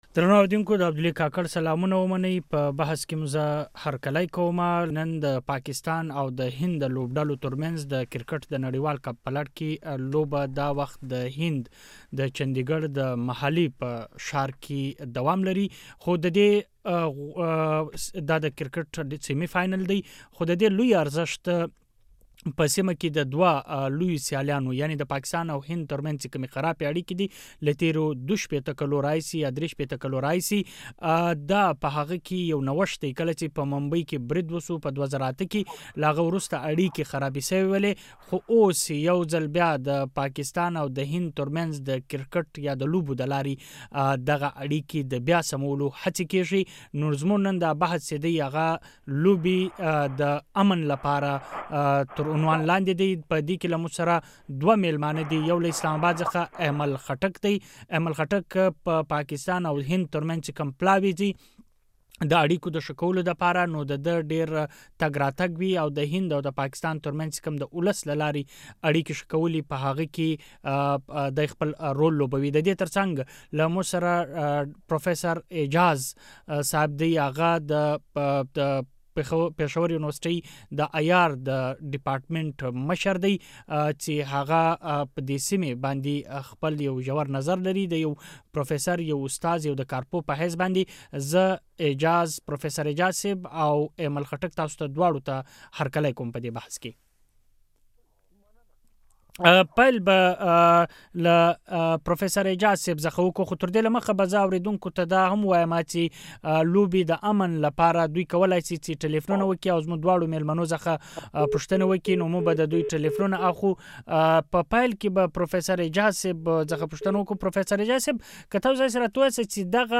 د مشال تر رڼا لاندې بحث هم دې موضوع ته ځانګړی